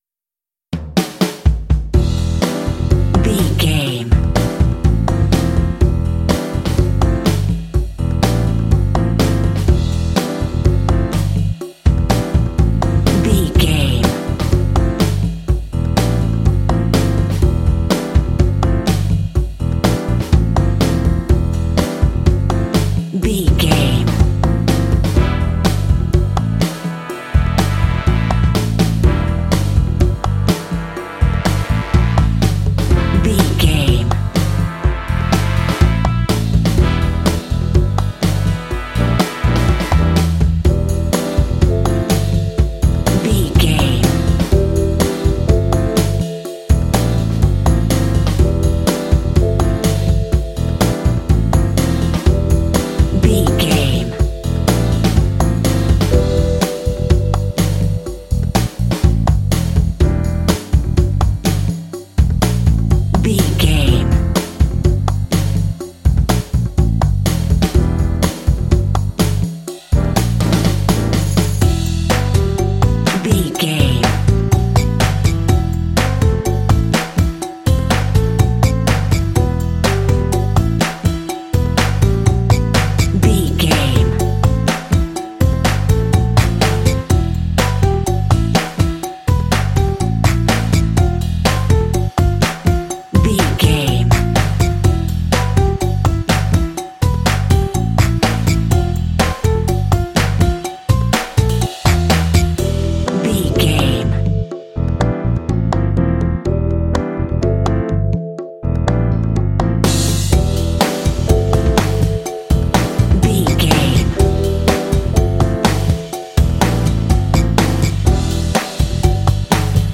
Phrygian
funky
happy
bouncy
groovy
piano
drums
brass
bass guitar
latin
jazz
Funk